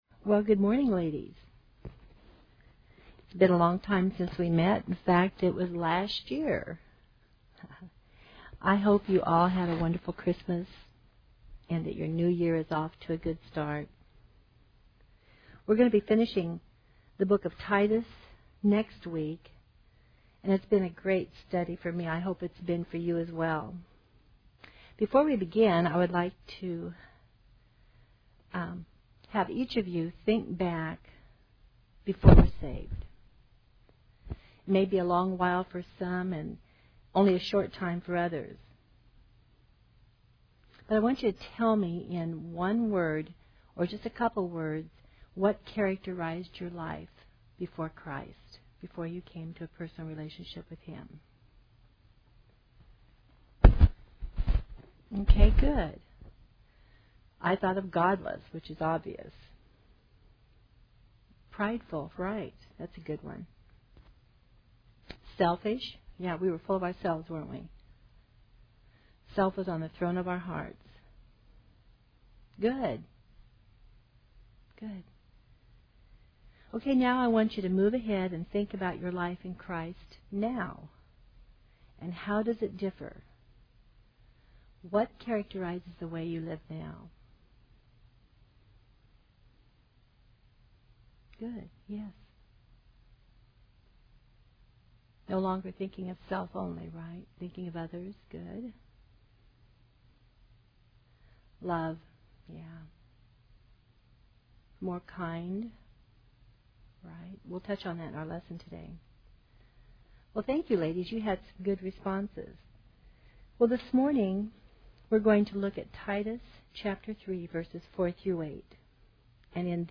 Women Women - Bible Study - Titus Audio ◀ Prev Series List Next ▶ Previous 9.